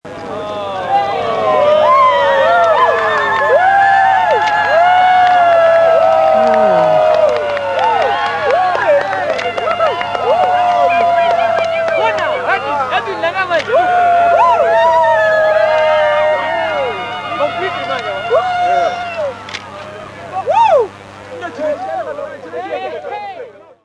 Ululations The Africans' Reaction to Totality
ululations.MP3